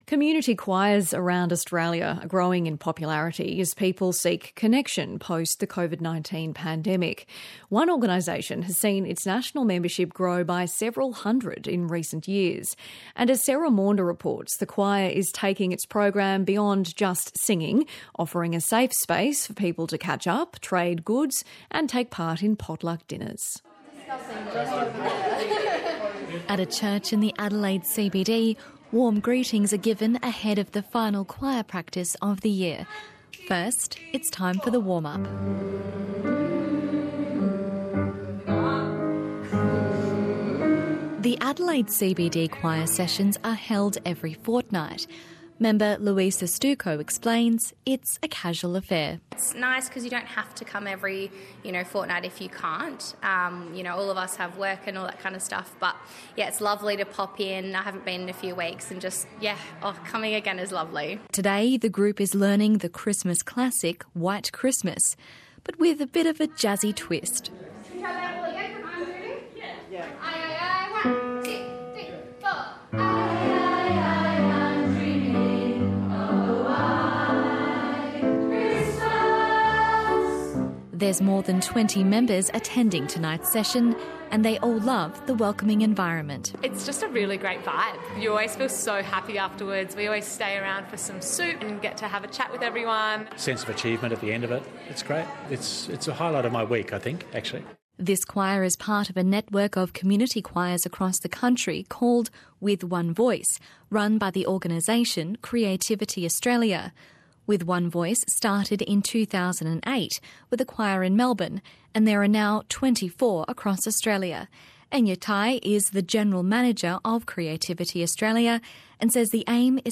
came along to our Adelaide CBD choir for their Christmas party, and had a chat to some of our amazing members! Click below to have a listen to her story about the rise of inclusive community choirs and the connection they provide.